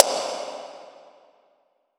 reverb.aif